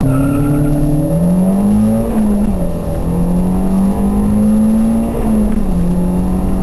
Beschleunigung 0-100 km/h 6,2 sec (wer's nicht glaubt hier gibts das video) Brumm auch wenns sich nicht so anhört das ist ein MX-5